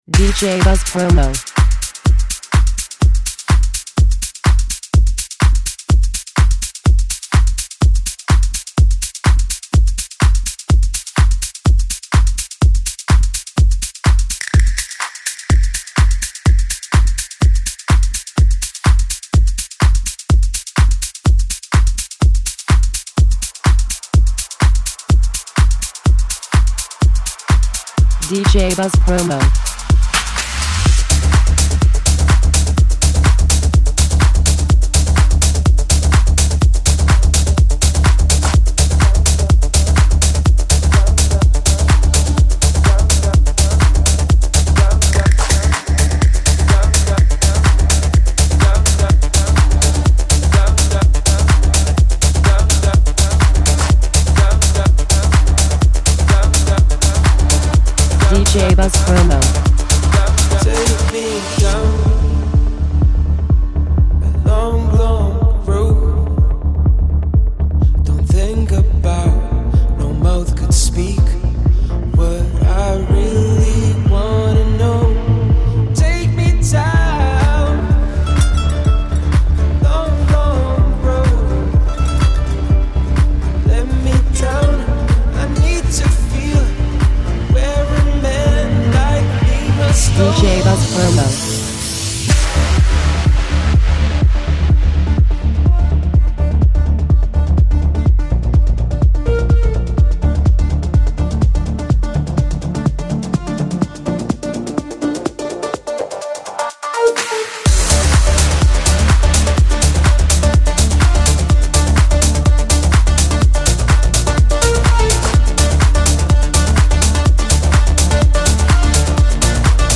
is now released in a club version with 2 Remixes
Tech House contaminations
Electro House